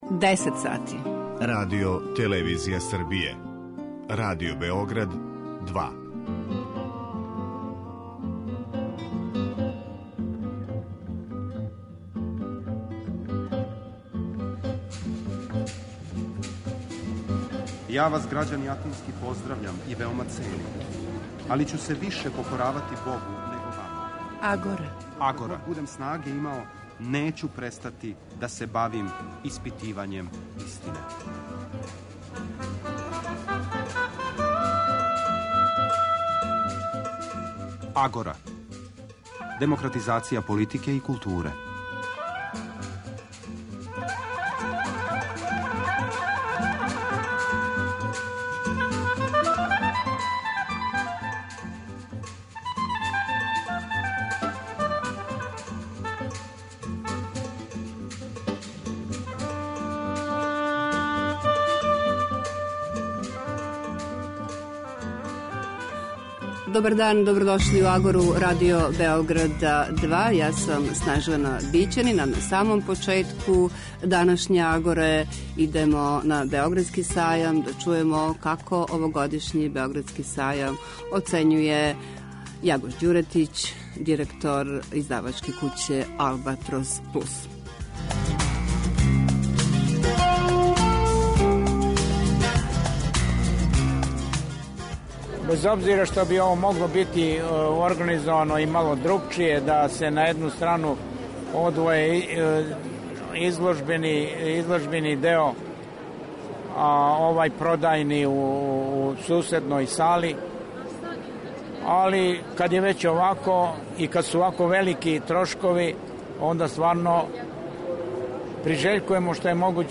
Чућемо и најзначајније акцента са ове промоције, а о прећуткивању Јасеновца, ксенофобији и годинама које нам се увек враћају, говори Славко Голштајн, који је и сам изгубио најближе у овом злогласном логору.